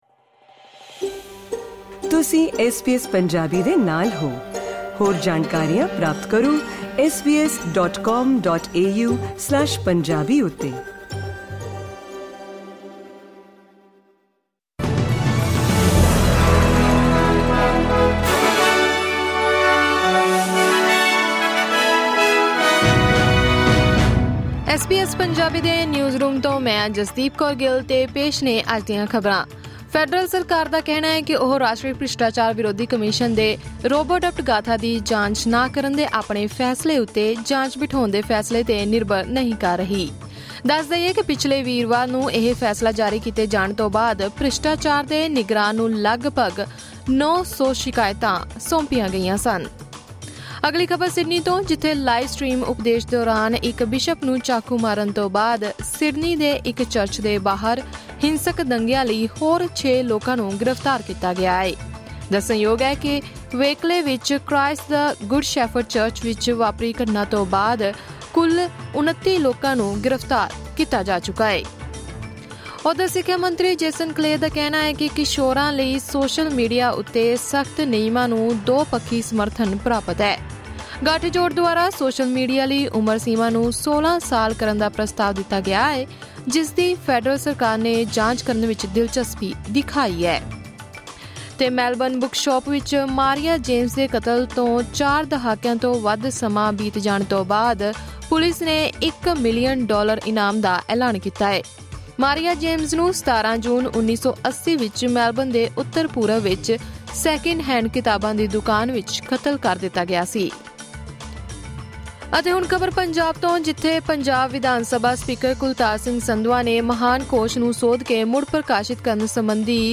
ਐਸ ਬੀ ਐਸ ਪੰਜਾਬੀ ਤੋਂ ਆਸਟ੍ਰੇਲੀਆ ਦੀਆਂ ਮੁੱਖ ਖ਼ਬਰਾਂ: 14 ਜੂਨ, 2024